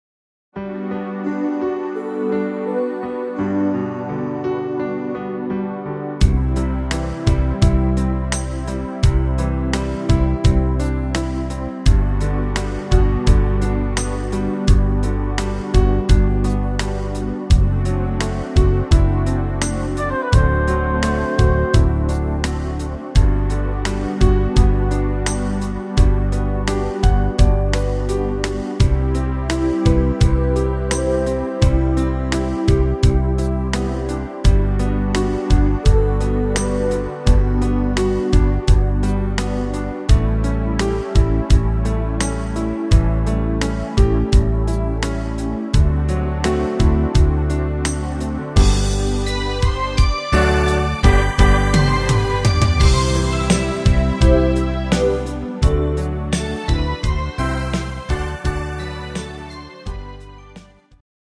instr. Synthesizer